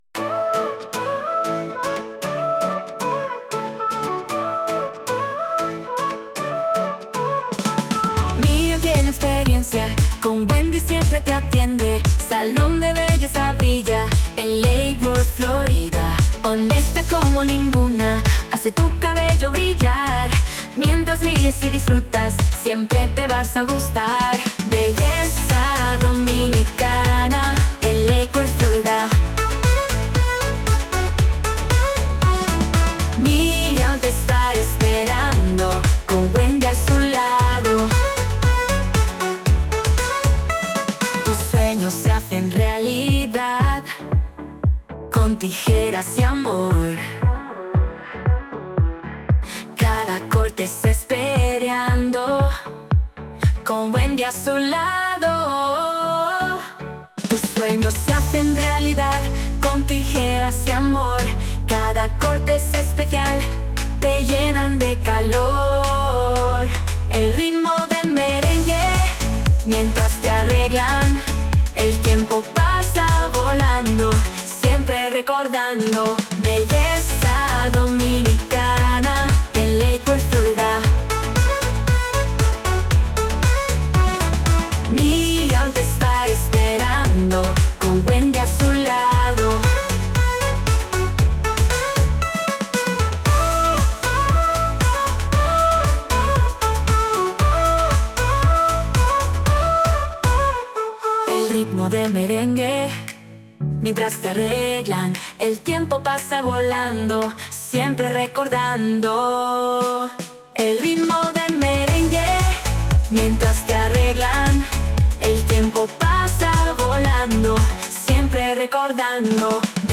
Music Genre: Merengue Pop (Female Singer)